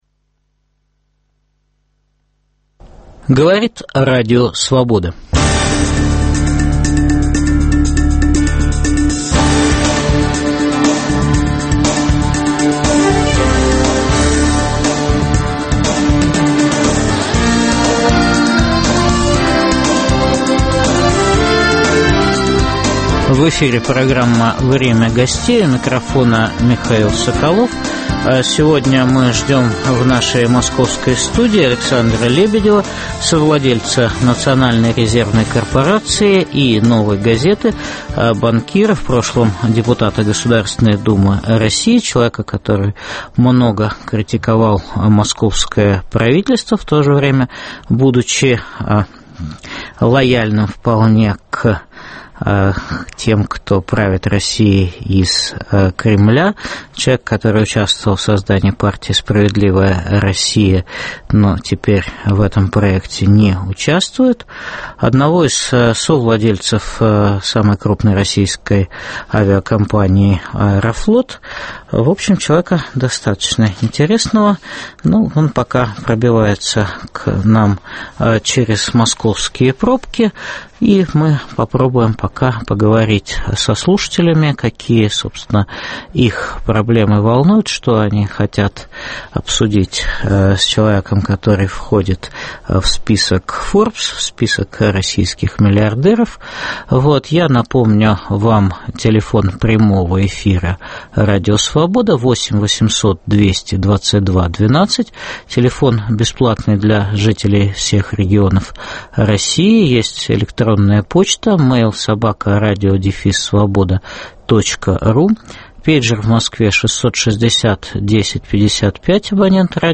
В программе выступит совладелец Национальной резервной корпорации и "Новой газеты" Александр Лебедев.